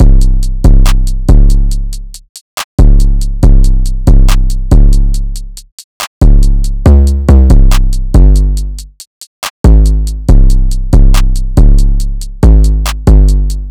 drumloop 8 (140 bpm).wav